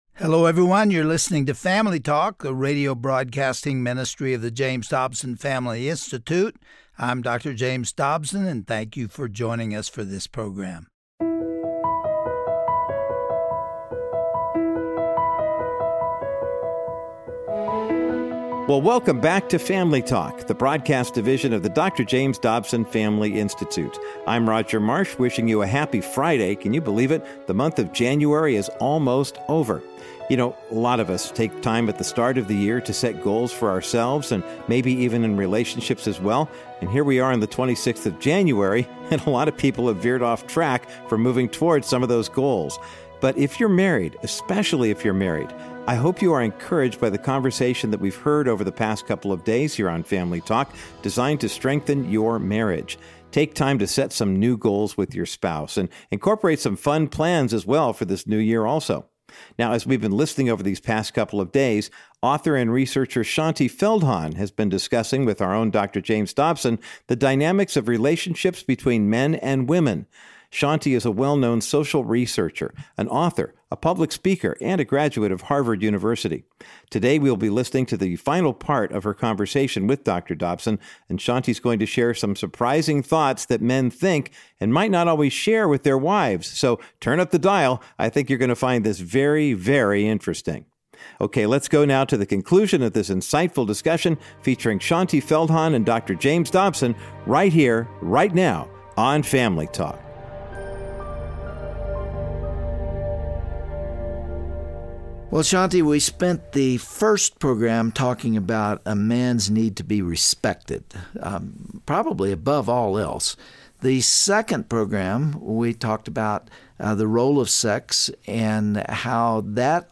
Today on this classic edition of Family Talk, Dr. James Dobson concludes his timeless interview with author Shaunti Feldhahn, revealing crucial information that women need to know about their husbands. Be enlightened as Shaunti and Dr. Dobson discuss the importance of romance in marriage, and why couples must intentionally carve out meaningful time for one another.